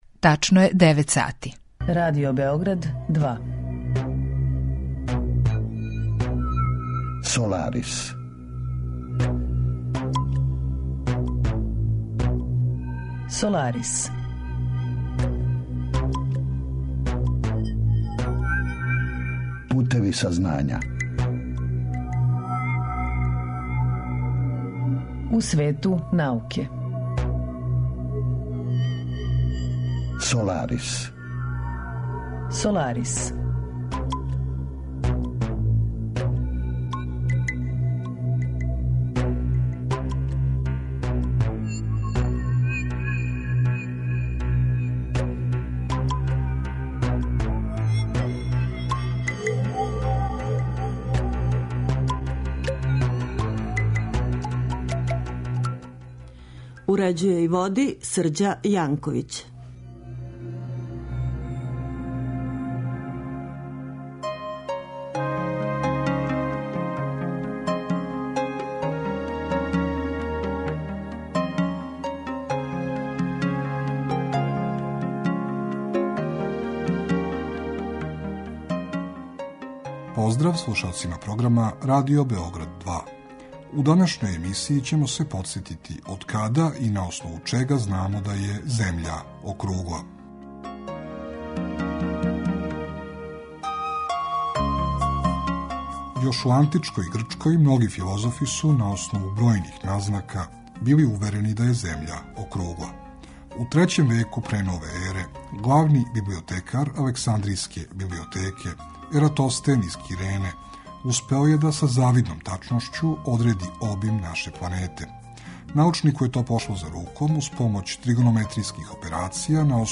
Саговорник: проф. др